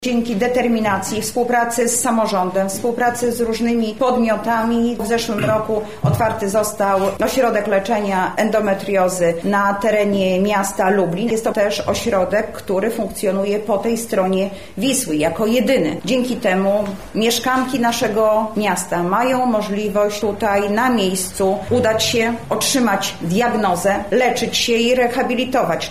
Anna Augustyniak-mówi Anna Augustyniak, Zastępca Prezydenta ds. Społecznych